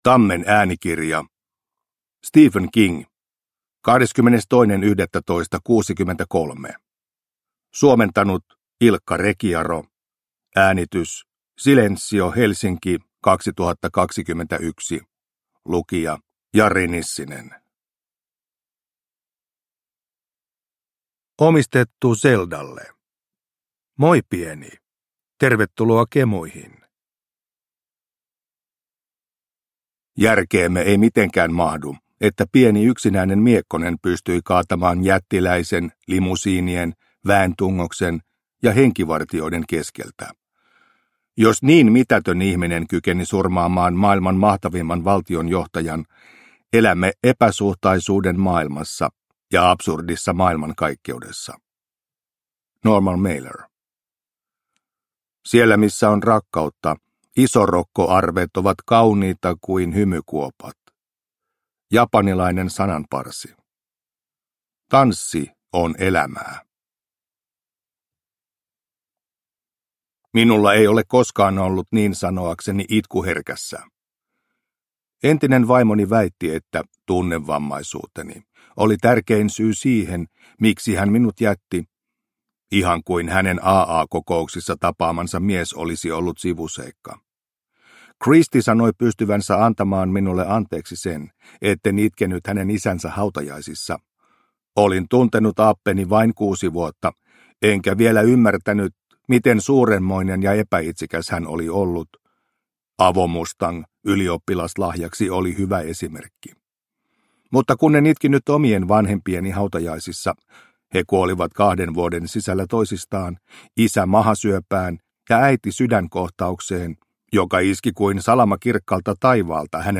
22.11.63 – Ljudbok – Laddas ner